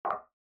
click-2.mp3